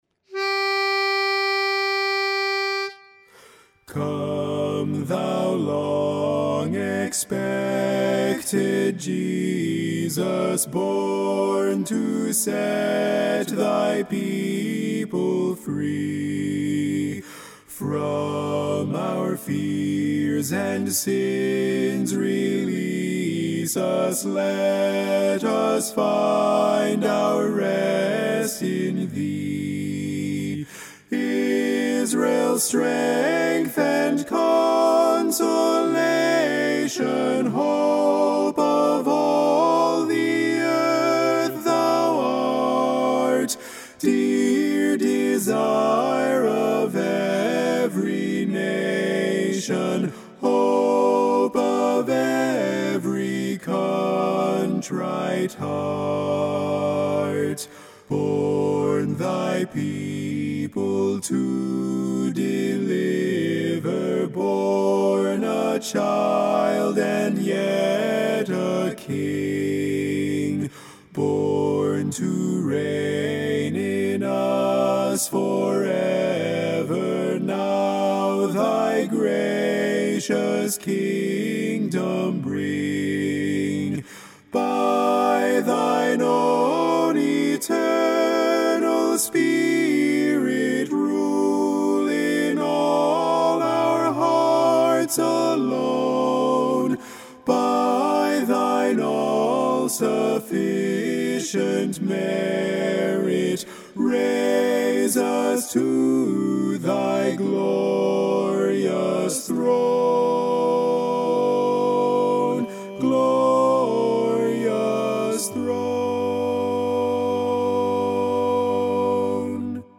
BARI - Come Thou Long Expected Jesus.mp3